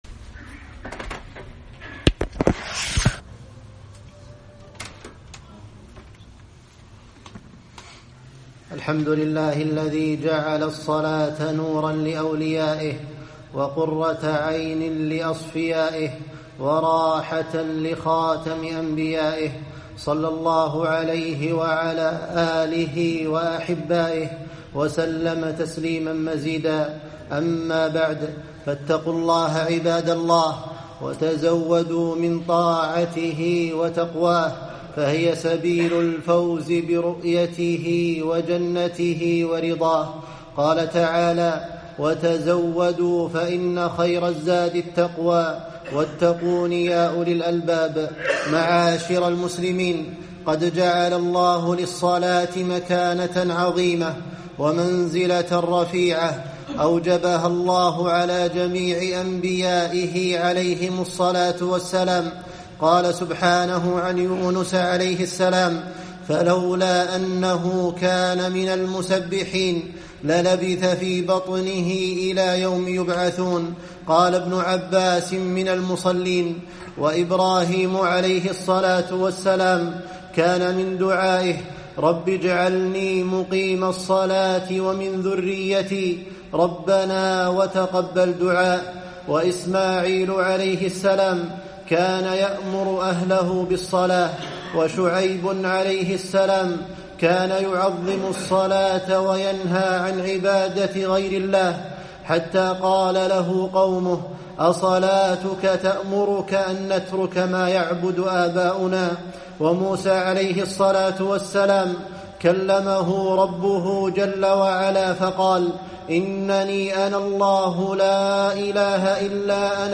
خطبة - الصلاة .. الصلاة